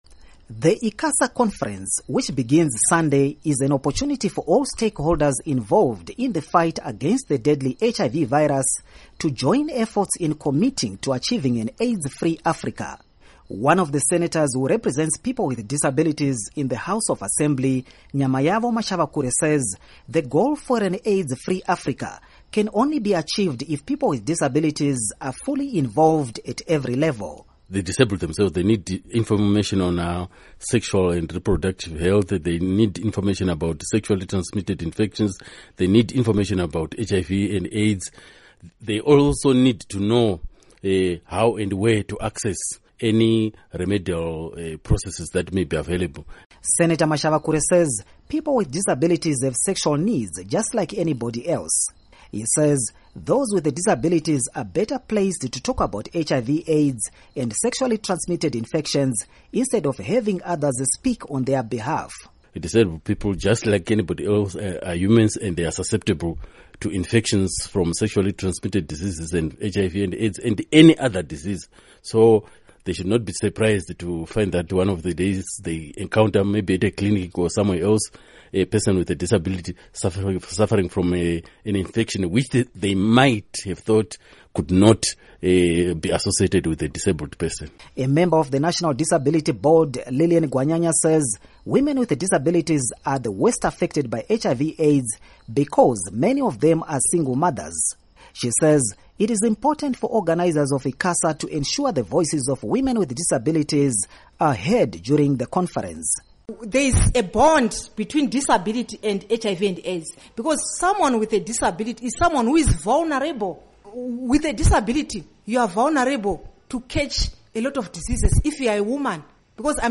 Report on ICASA